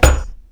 Shield6.wav